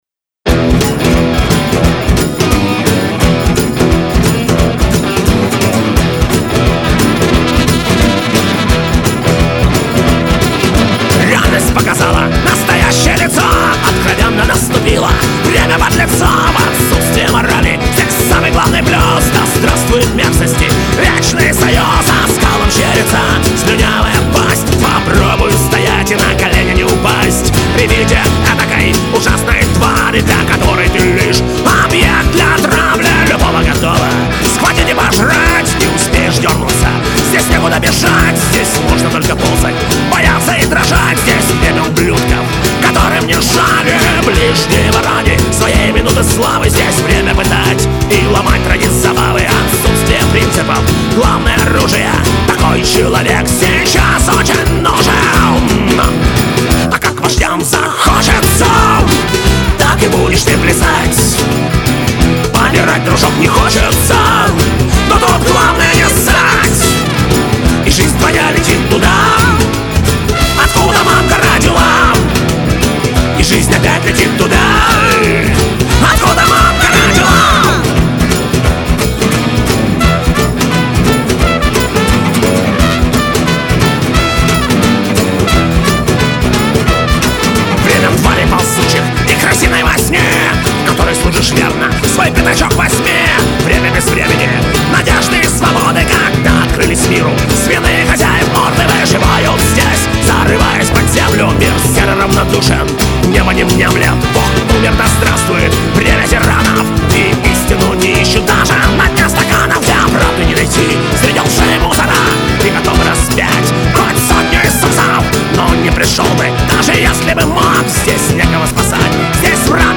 Жанр: бард-панк
вокал, гитары
бэк, перкуссия, скиффл-инструменты.
ритм-гитара.
бас.
джа-кахон.
труба.
виолончель.